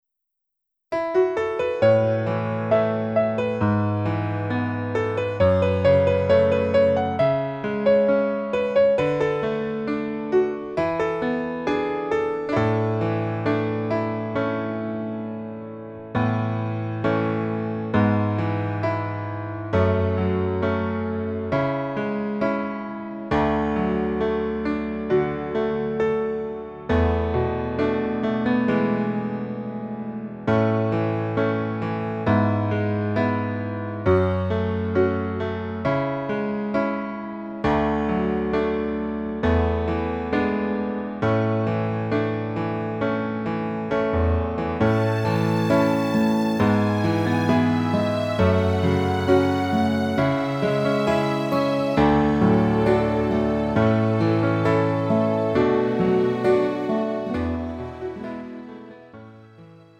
음정 (-1키)
장르 가요 구분 Pro MR